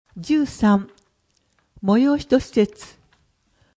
声の「広報はりま」12月号
声の「広報はりま」はボランティアグループ「のぎく」のご協力により作成されています。